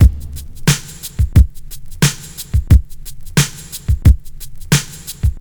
89 Bpm High Quality Hip-Hop Drum Loop Sample F# Key.wav
Free drum groove - kick tuned to the F# note.
89-bpm-high-quality-hip-hop-drum-loop-sample-f-sharp-key-Dze.ogg